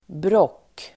Uttal: [bråk:]